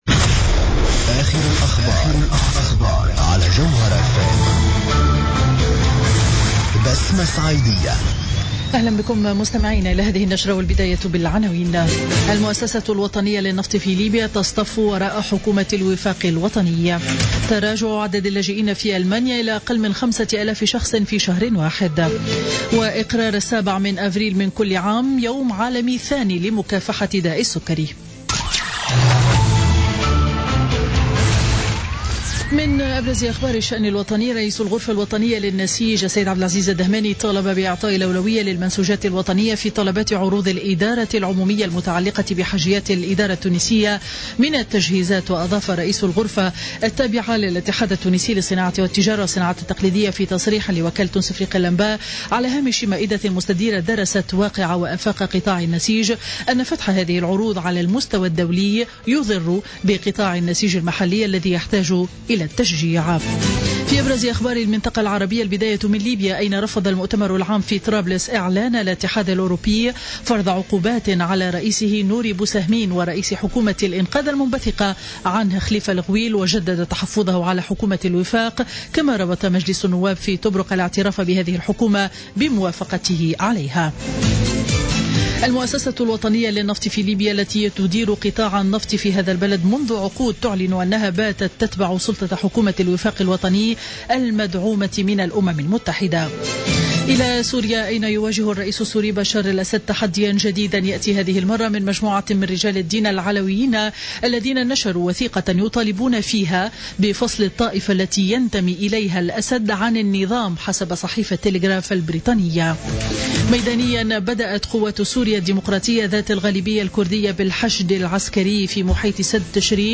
نشرة أخبار منتصف النهار ليوم الأحد 3 أفريل 2016